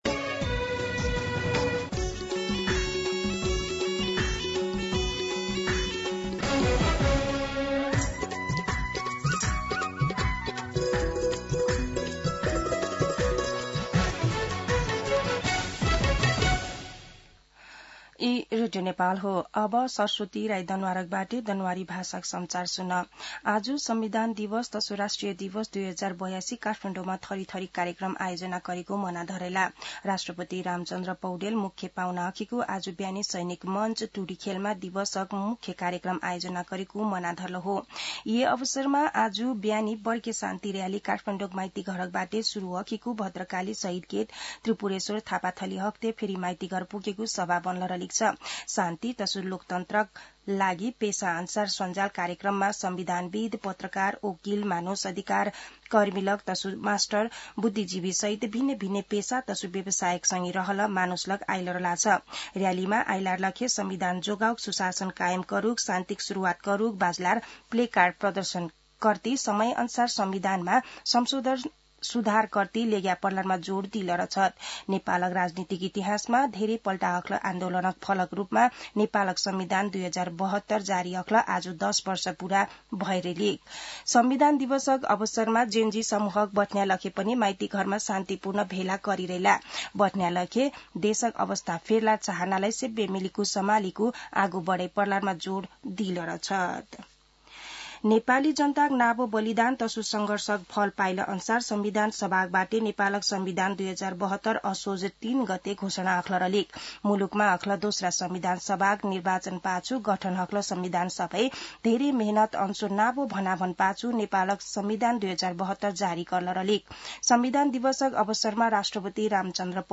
दनुवार भाषामा समाचार : ३ असोज , २०८२
Danuwar-News-5.mp3